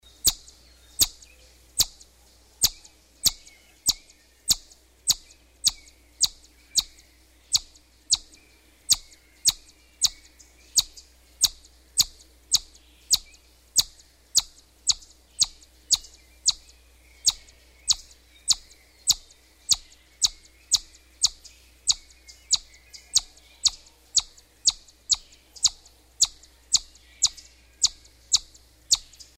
На этой странице собраны разнообразные звуки бурундуков — от веселого стрекотания до любопытного писка.
Звуки дикого бурундука